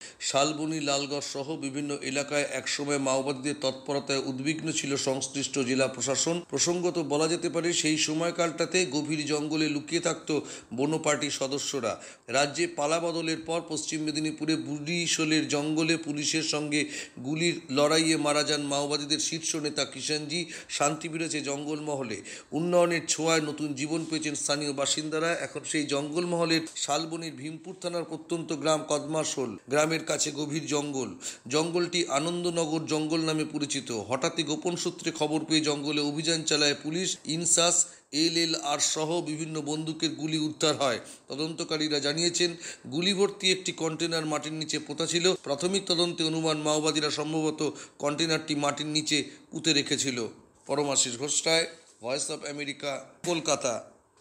প্রতিবেদন।